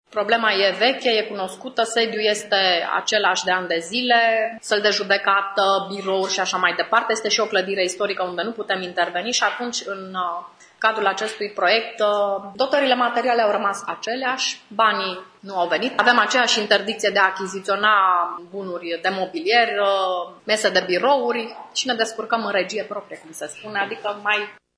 Este una dintre concluziile formulate de președintele Tribunalului Mureș, judecătorul Ioana Lucaci la bilanțul instituției pe anul 2014.
Problemele financiare, patrimoniale ale Tribunalului Mureș sunt aceleași din anii anteriori, a subliniat președintele Ioana Lucaci, adică în schema de personal lipsesc 2 judecători, iar spațiile sunt insuficiente: